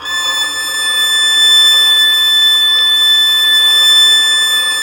Index of /90_sSampleCDs/Roland - String Master Series/STR_Violin 4 nv/STR_Vln4 no vib
STR VLN BO0R.wav